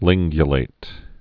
(lĭnggyə-lāt)